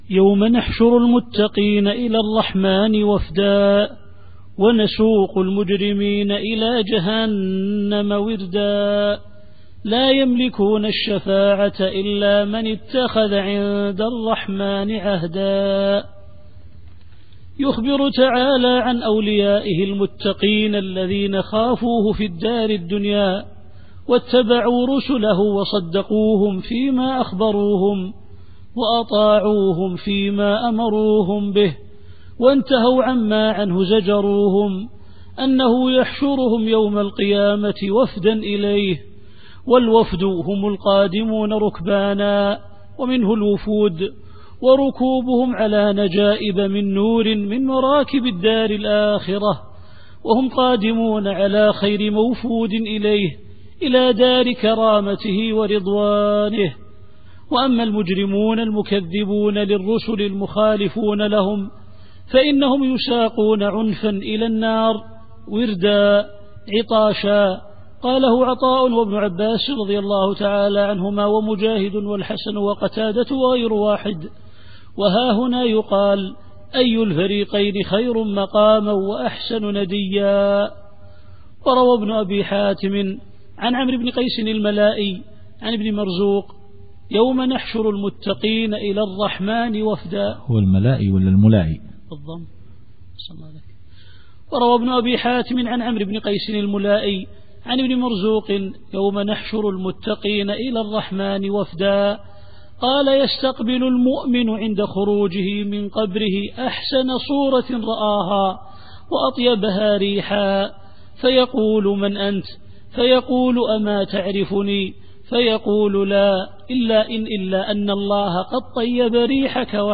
التفسير الصوتي [مريم / 85]